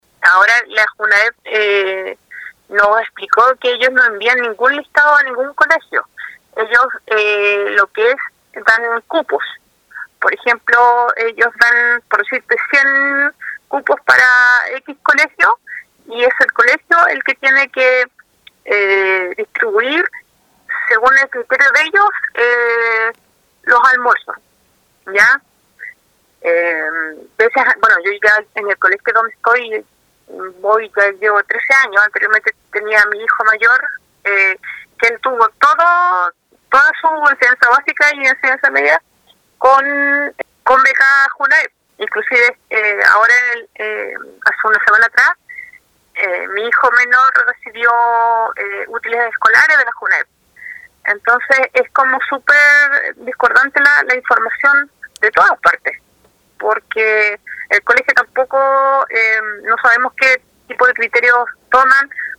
28-APODERADA-POR-BECA-JUNAEB-2.mp3